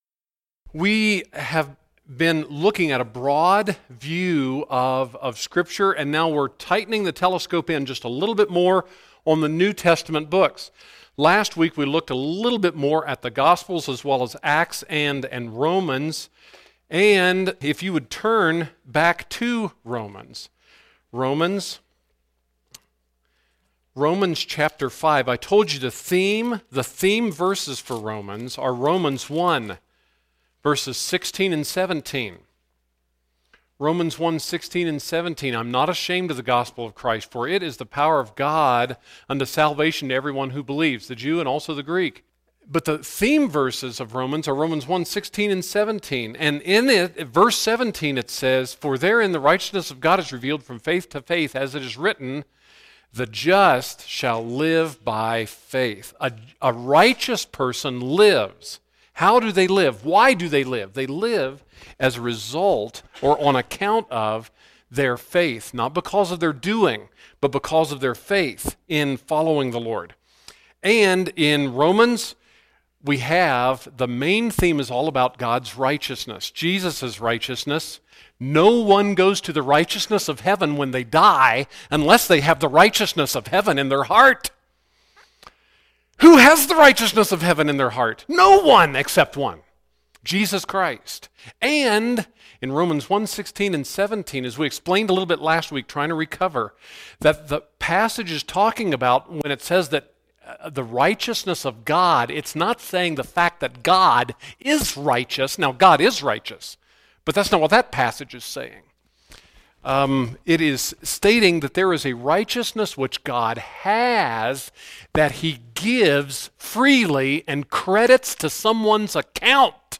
Sunday School…